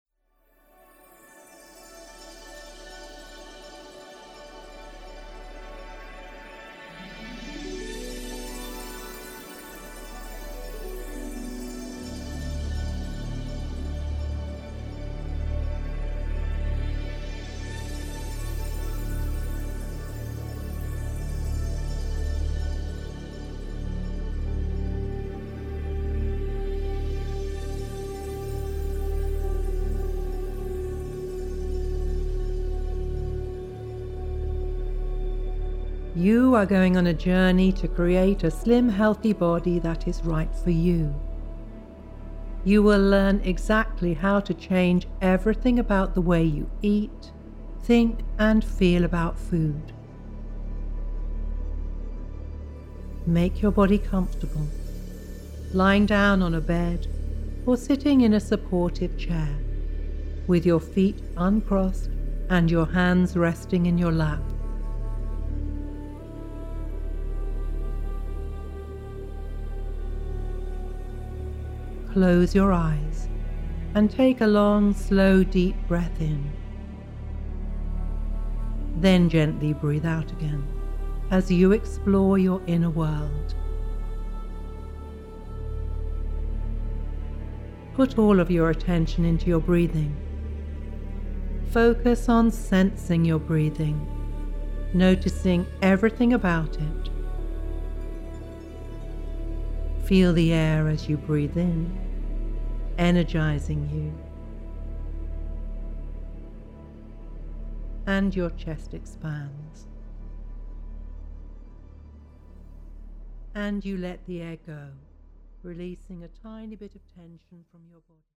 this comprehensive series consists of 12 guided meditations embedded with Hemi-Sync®.